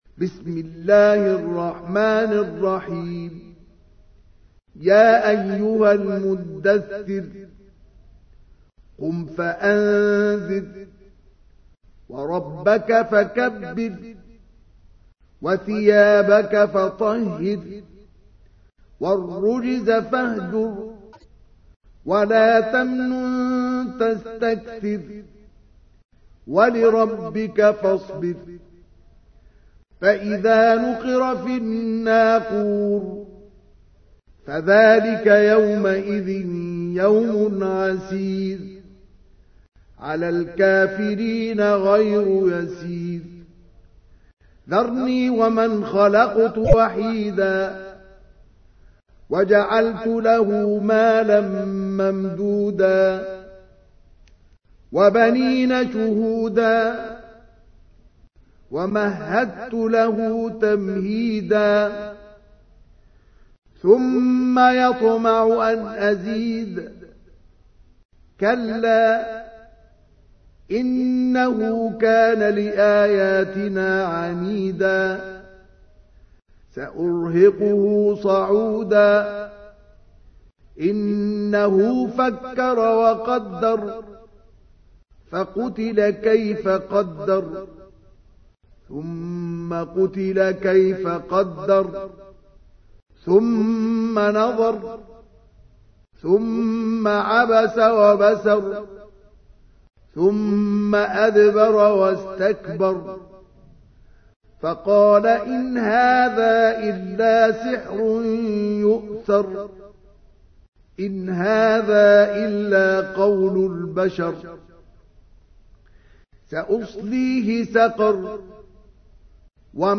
تحميل : 74. سورة المدثر / القارئ مصطفى اسماعيل / القرآن الكريم / موقع يا حسين